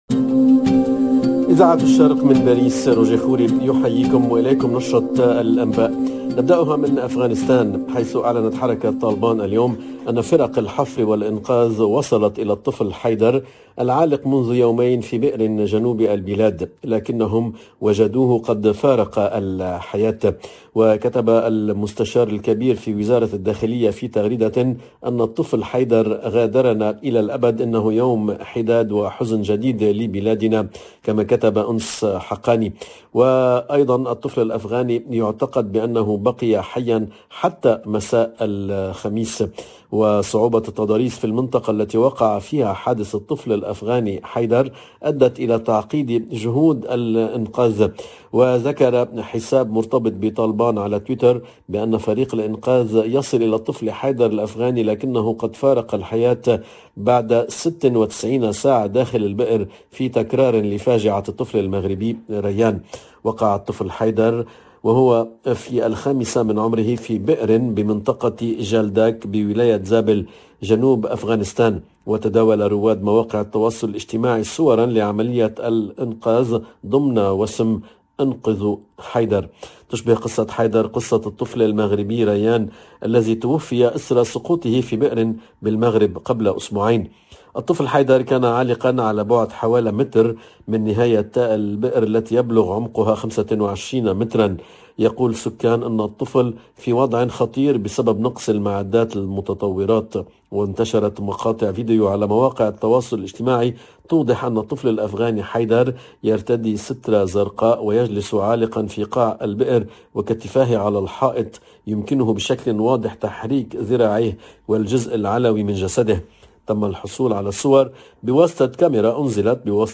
LE JOURNAL EN LANGUE ARABE DE LA MI-JOURNEE DU 18/02/22